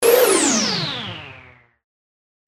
/ F｜演出・アニメ・心理 / F-10 ｜ワンポイント マイナスイメージ_
低下 07 ステータスエフェクト パワーダウン3
ピユーン